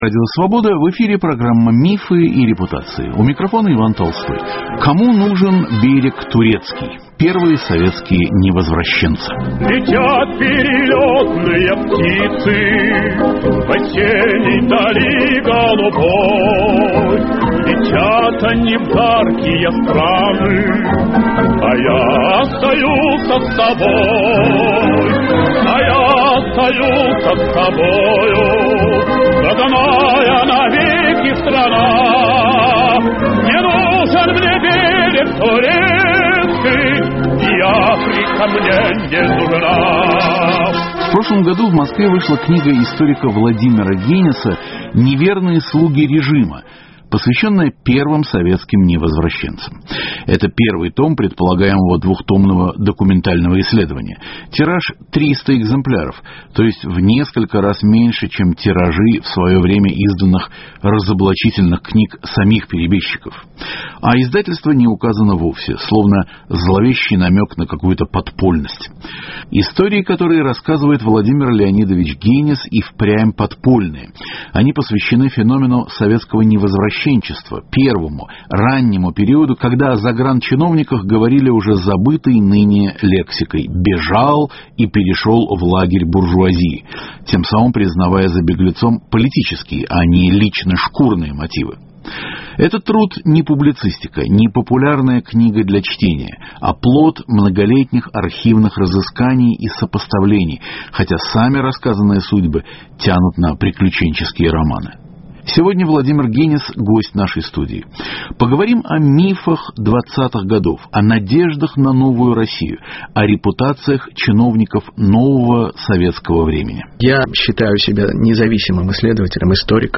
Кому нужен берег турецкий: Первые советские невозвращенцы. Гость программы - московский историк